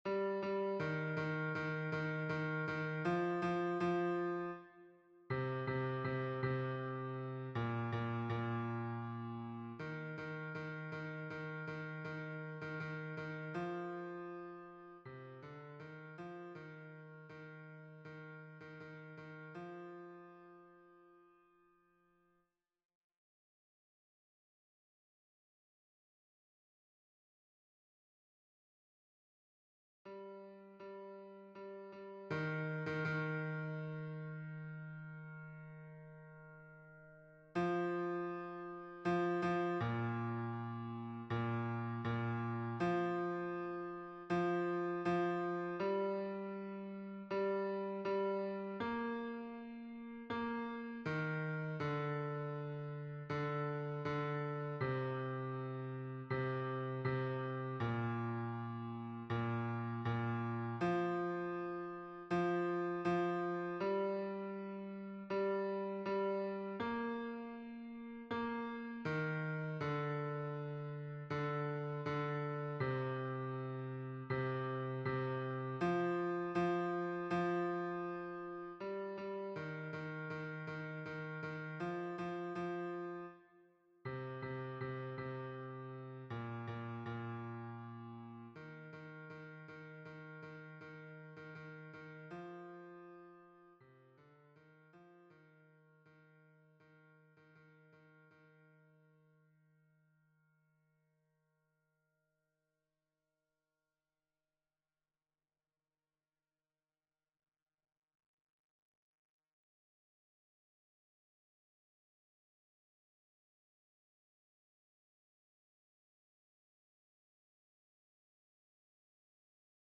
MP3 version piano
Baryton